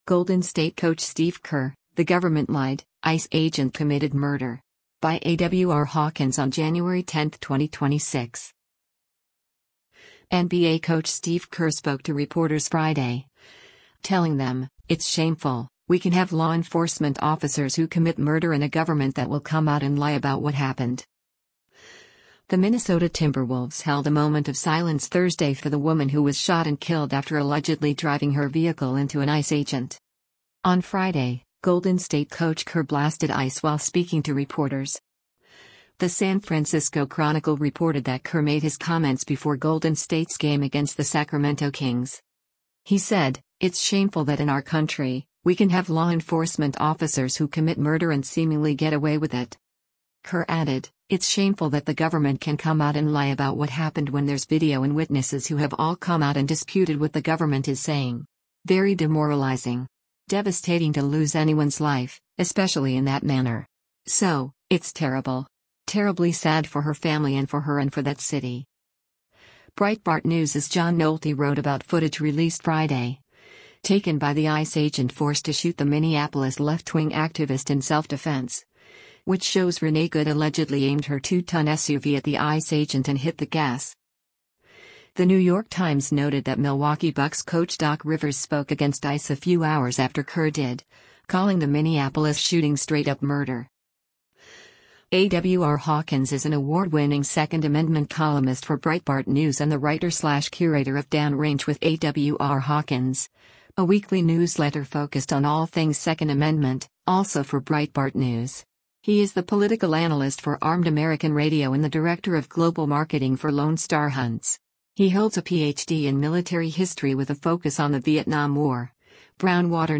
NBA coach Steve Kerr spoke to reporters Friday, telling them, “It’s shameful…we can have law enforcement officers who commit murder” and a government that will “come out and lie about what happened.”